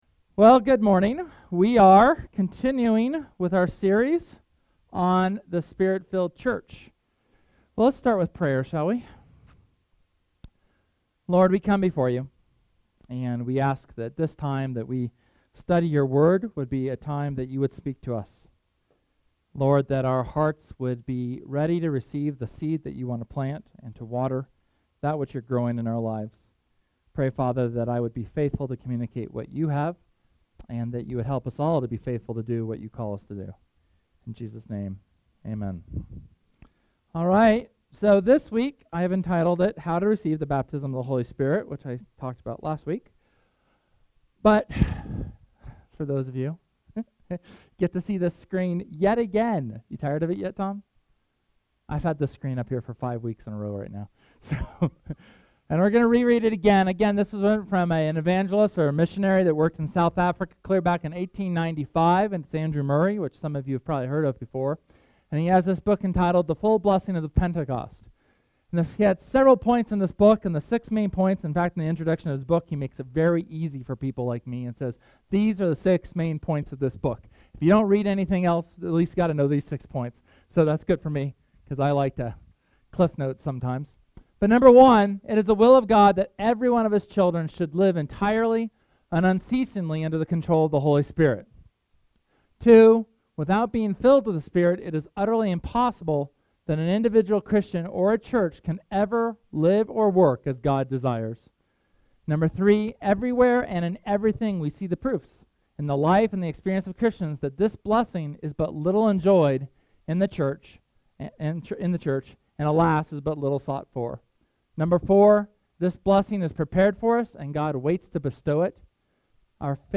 Sermon from August 10th on receiving the baptism of the Holy Spirit.